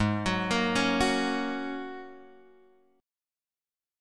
G# 9th Suspended 4th